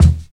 60 KICK.wav